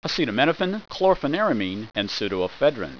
Pronunciation
(a seet a MIN oh fen, klor fen IR a meen, & soo doe e FED rin)